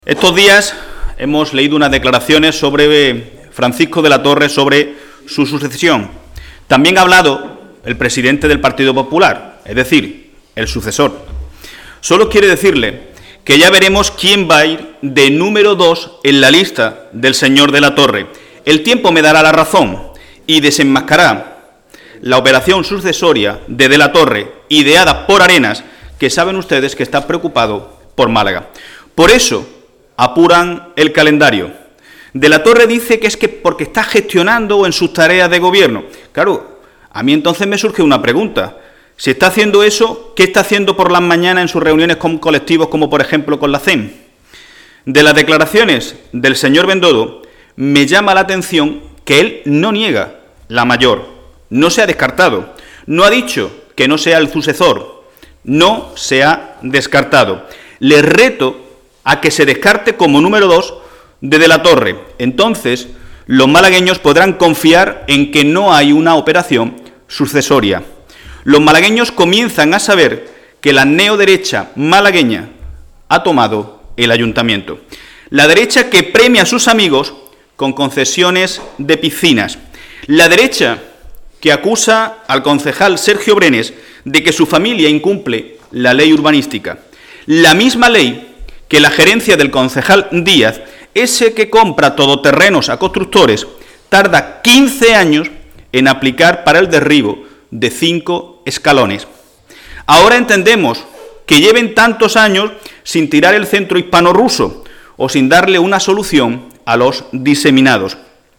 El secretario general del PSOE malagueño, Miguel Ángel Heredia, ha retado hoy en rueda de prensa al presidente provincial del PP, Elías Bendodo, "a que se descarte como número dos de De la Torre".